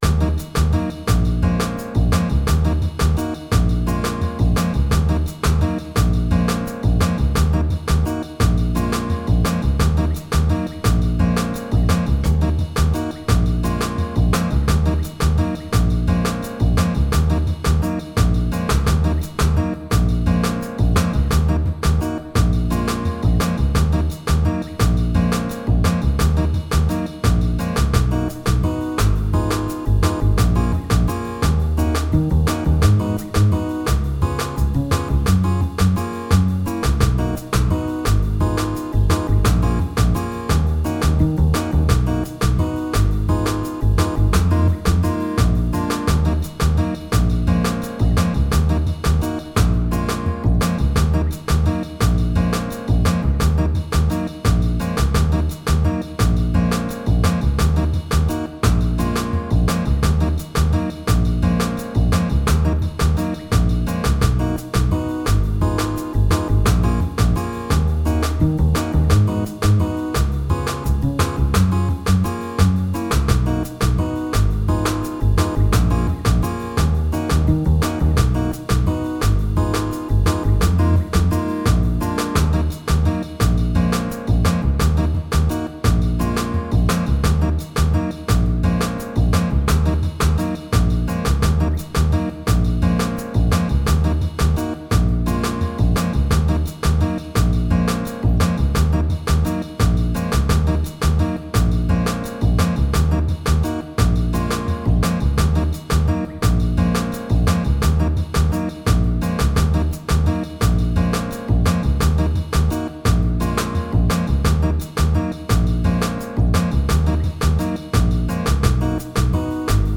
altosax